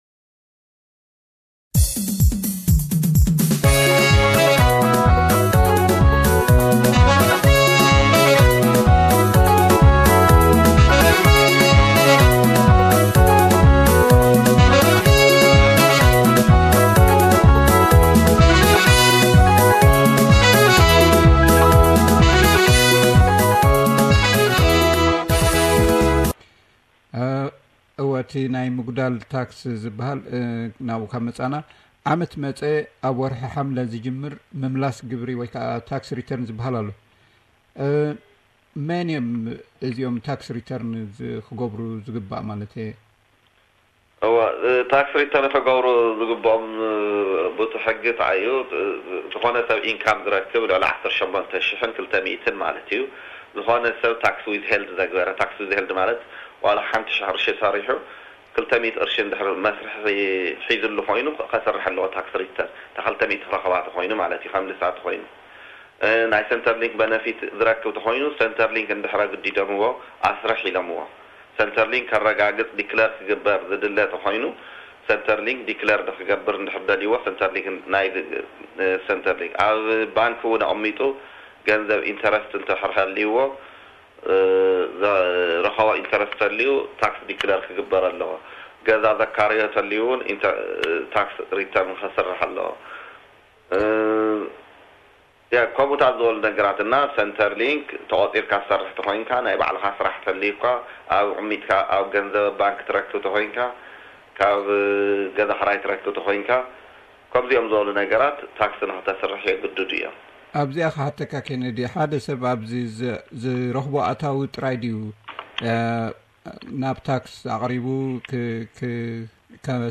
Tax interview